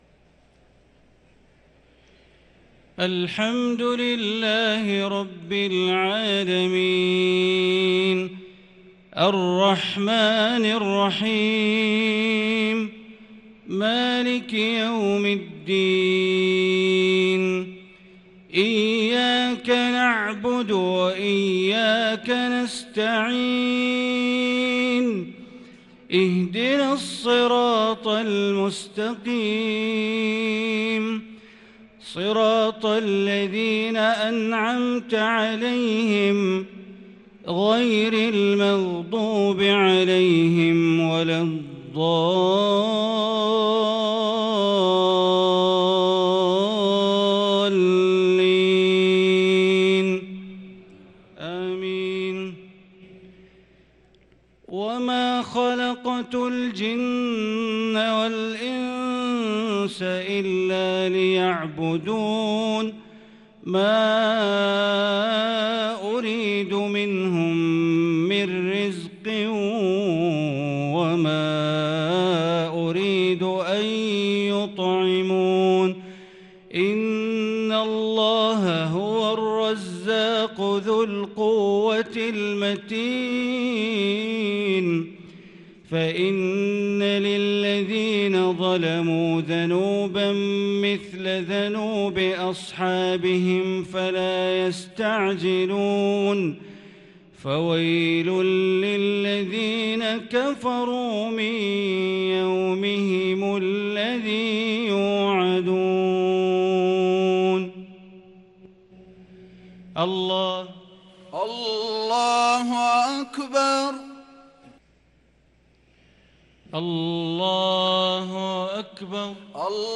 صلاة العشاء للقارئ بندر بليلة 22 رمضان 1443 هـ
تِلَاوَات الْحَرَمَيْن .